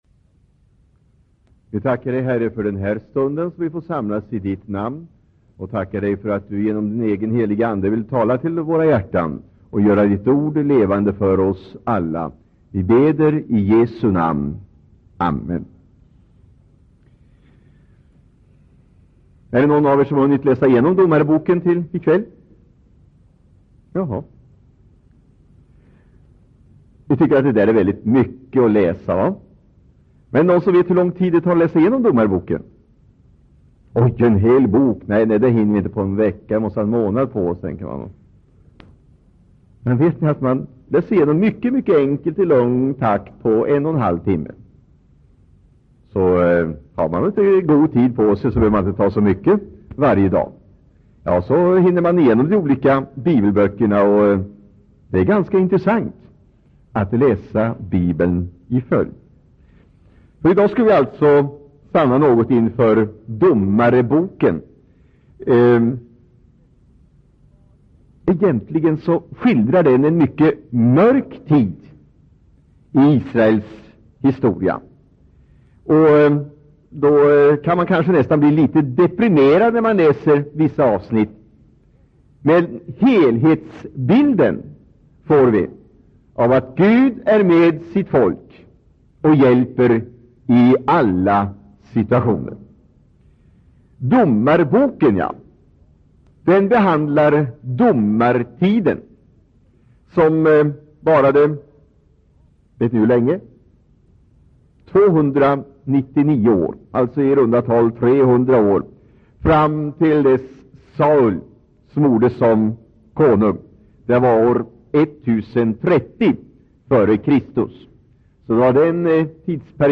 Inspelad i Citykyrkan, Stockholm 1984-09-26.